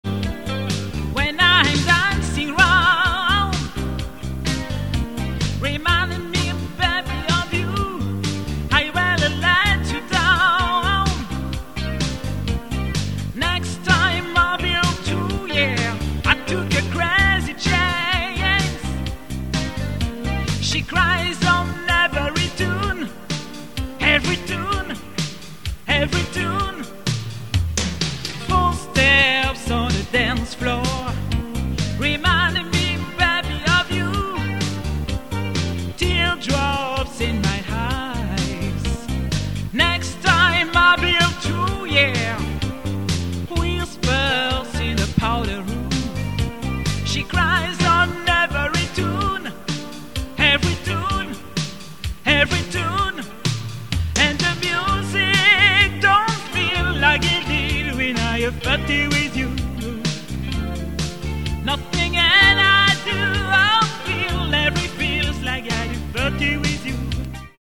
EXTRAIT PIANO-BAR